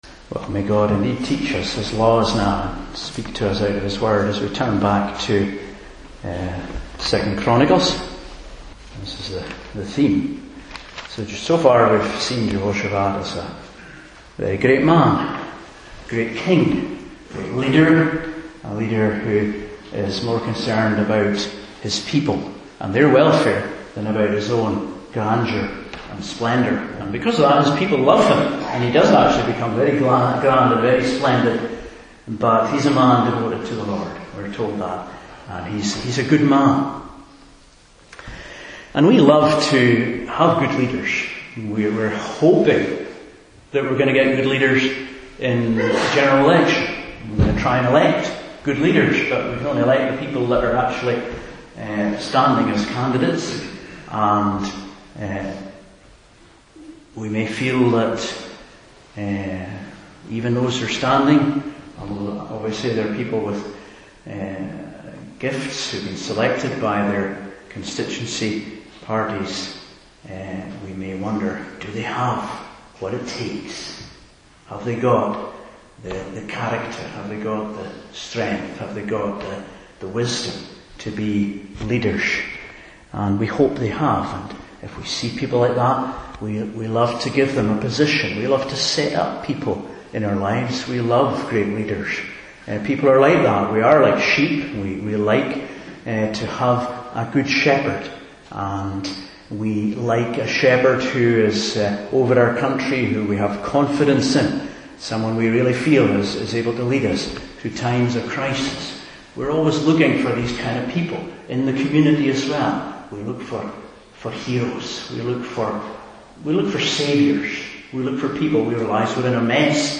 2 Chronicles 19:1-3 Service Type: Morning Service We look for leaders to save us from the human mess.